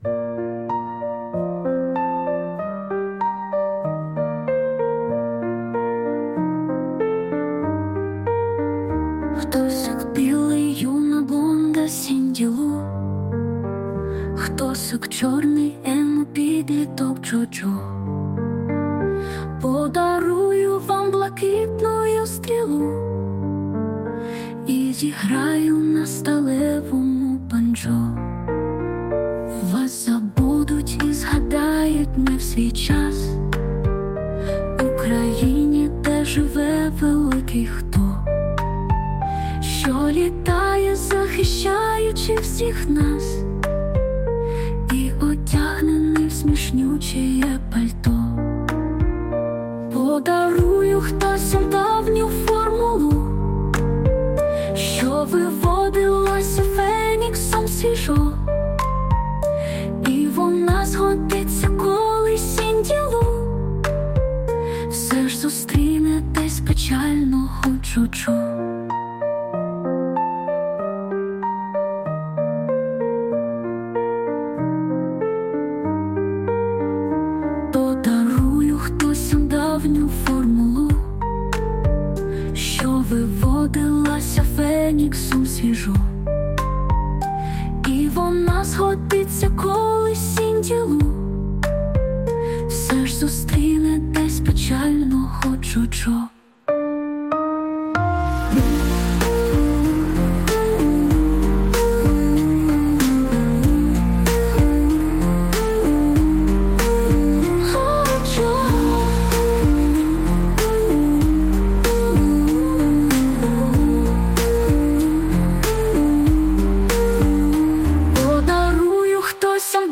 Хтосіки. (Одна з пісень на мої слова.